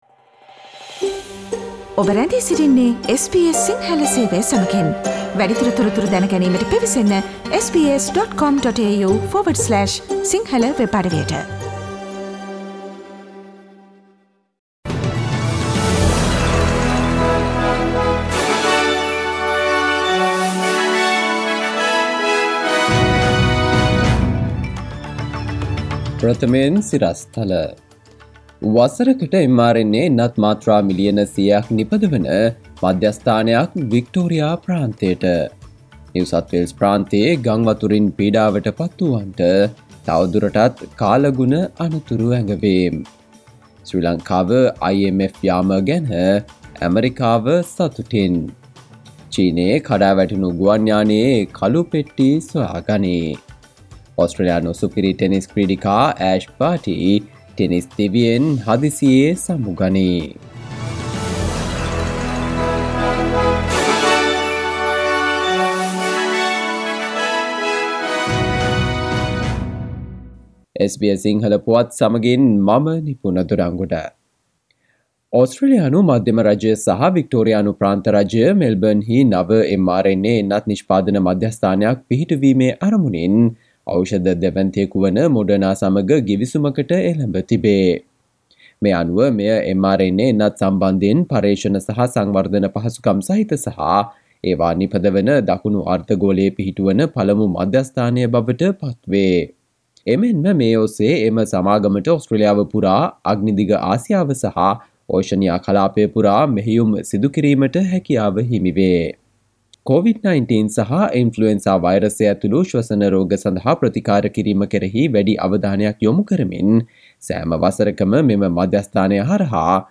සවන්දෙන්න 2022 මාර්තු 24 වන බ්‍රහස්පතින්දා SBS සිංහල ගුවන්විදුලියේ ප්‍රවෘත්ති ප්‍රකාශයට...